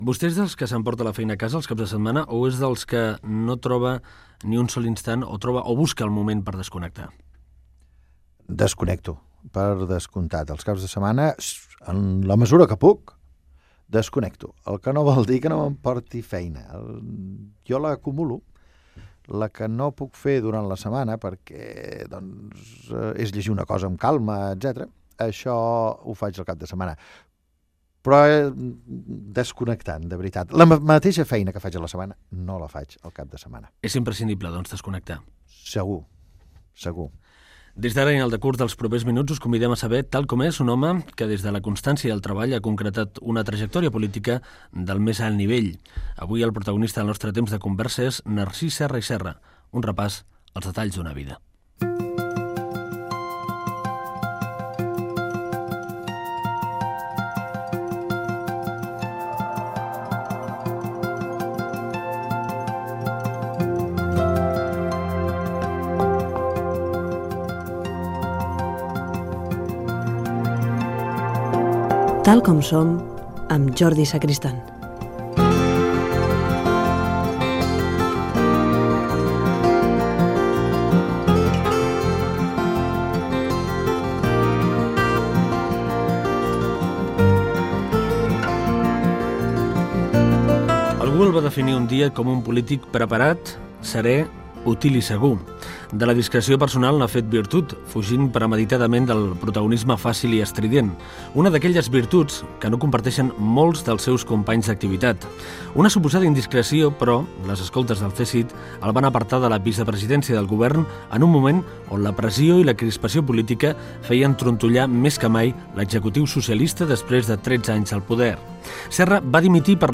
Fragment d'una entrevista al polític Narcís Serra.